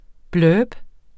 Udtale [ ˈblœːb ]